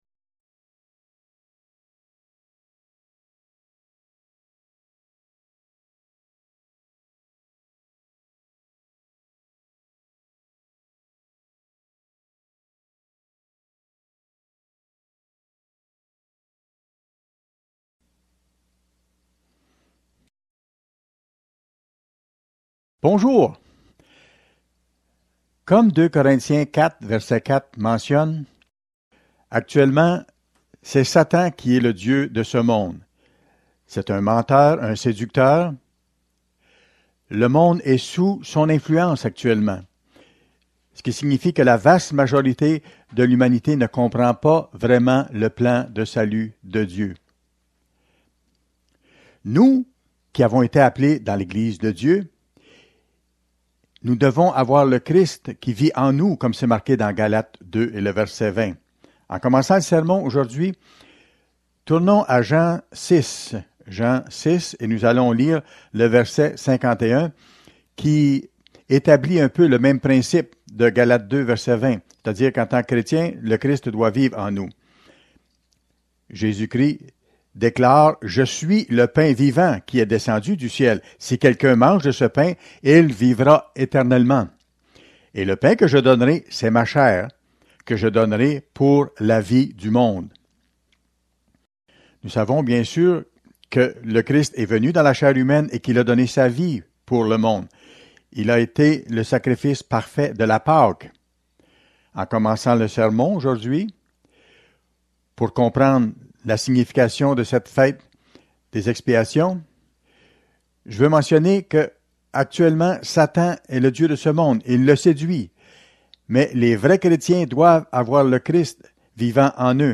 Dans ce sermon, nous verrons le rôle de cette Fête dans le plan de Dieu, la dualité des symboles anciens (le bouc émissaire et le bouc immolé) de cette Fête et pourquoi nous jeûnons pendant ce jour particulier.